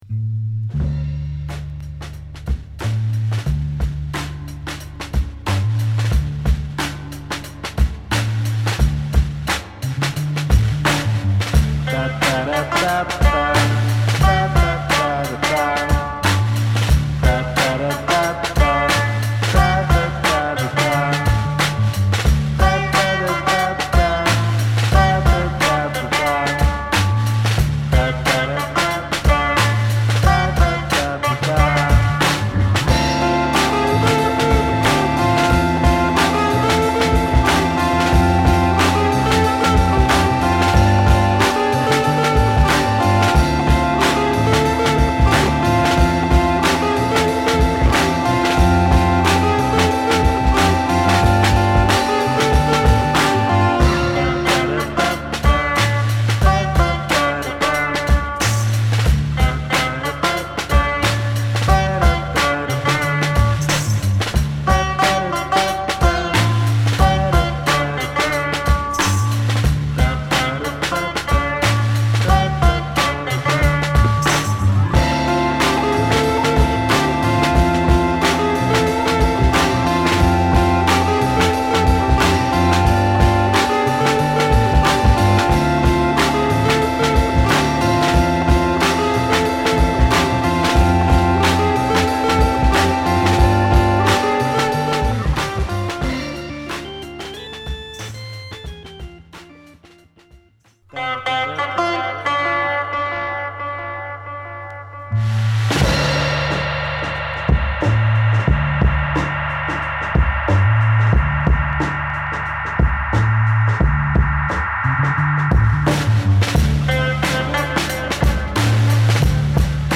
こちら激ヤバ・シタール・アブストラクト・ファンク！！